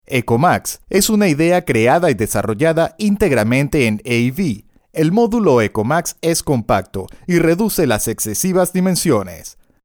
spanisch Südamerika
Sprechprobe: eLearning (Muttersprache):